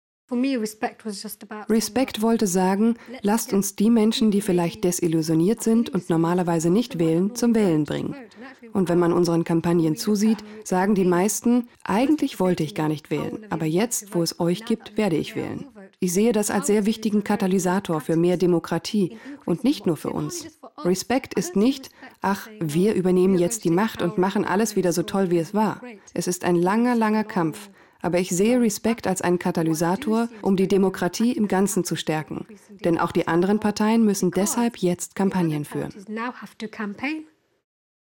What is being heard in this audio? Commercial (Werbung), Presentation